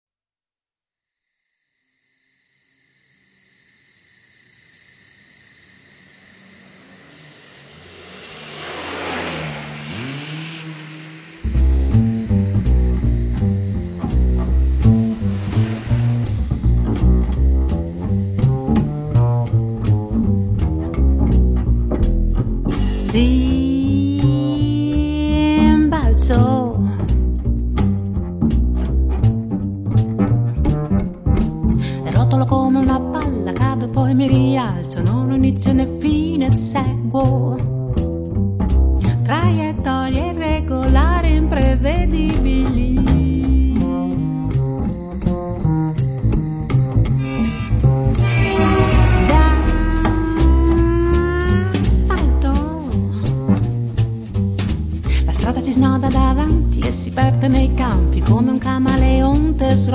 voce, piano,fisarmonica, tastiere
contrabbasso
batteria e percussioni
C'è molta teatralità nel modo di cantare